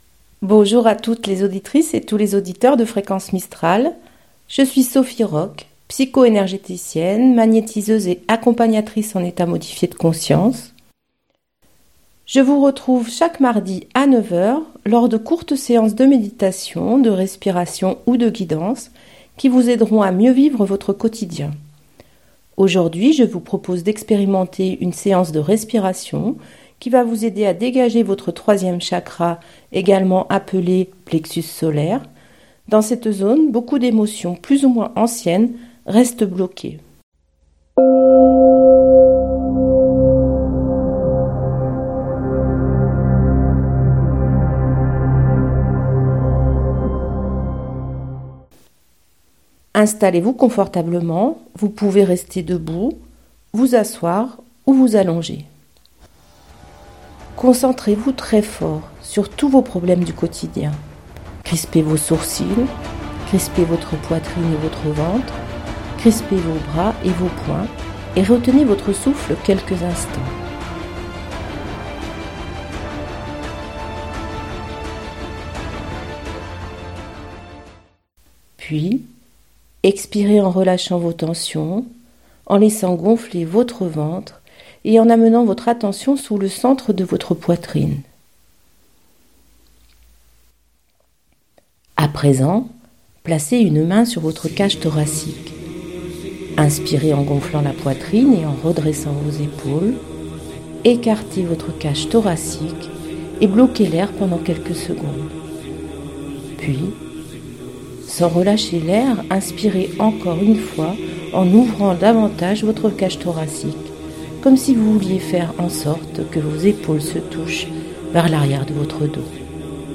Sur les ondes de fréquence mistral, je vous propose l'émission " En chemin vers soi ", deux fois par mois (second et quatrième mardi du mois) en direct, afin d'expérimenter des séances très simples : de respiration, de méditation, de visualisation. Le but de ces séances est de vous ramener vers votre intériorité, de vous aider à retrouver votre centre pour mieux vous sentir dans votre vie de tous les jours.